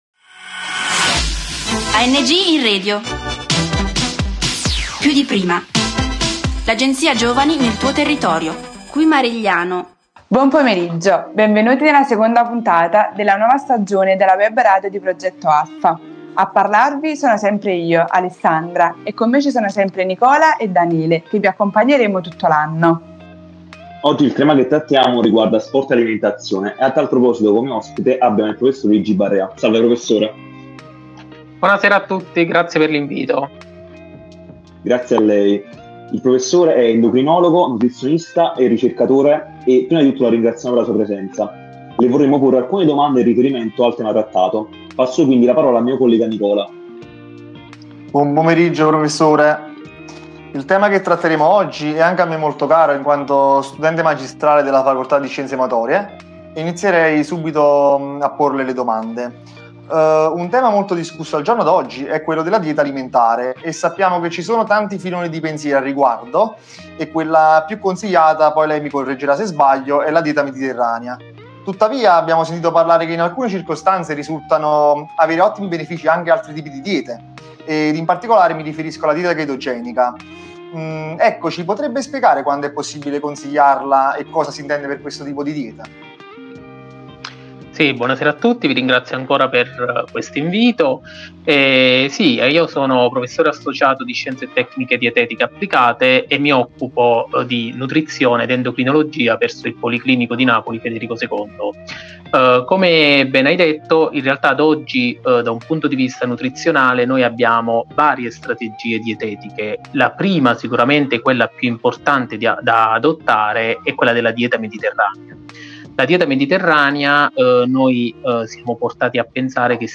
Da poco la comunità internazionale ha festeggiato il compleanno del riconoscimento della Dieta Mediterranea come patrimonio immateriale dell’umanità. Ospite in studio